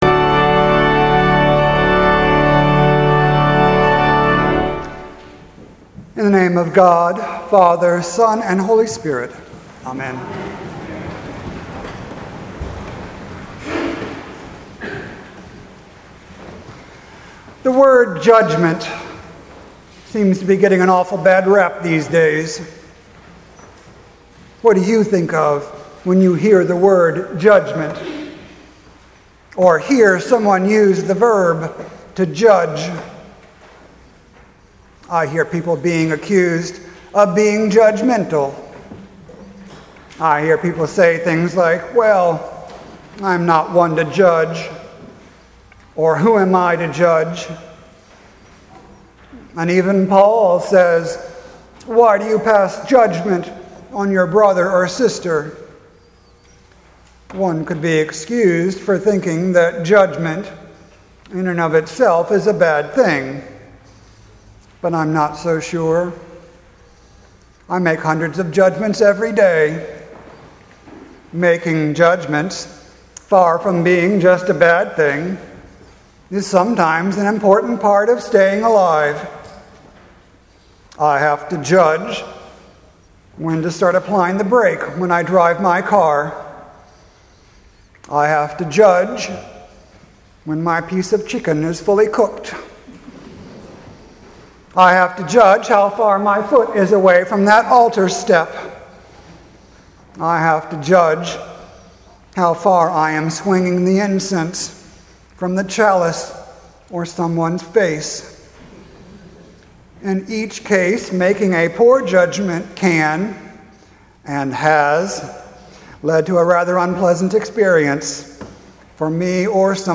Sermon for Sunday, September 17th, 2017.